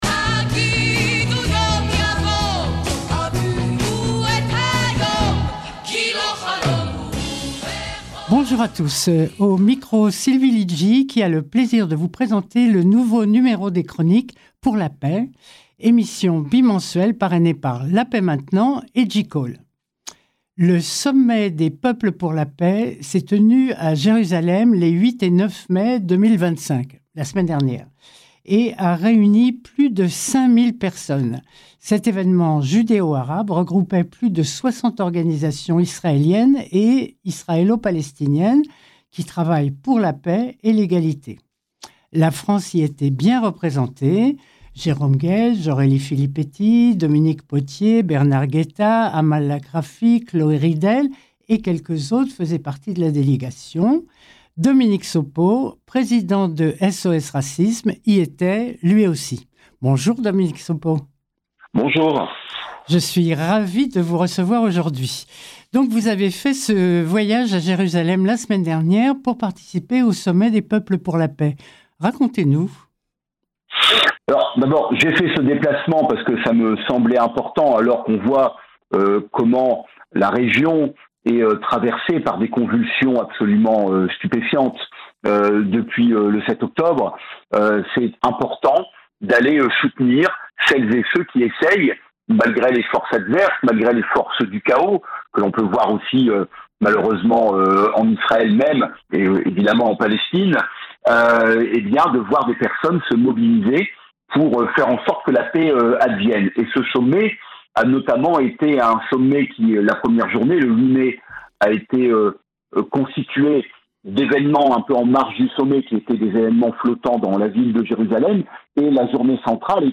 Entretien avec Dominique Sopo - JCall
CLIQUER SUR LA FLÈCHE (LIEN) CI-DESSOUS POUR ÉCOUTER L’INTERVIEW SUR RADIO SHALOM (94.8 fm)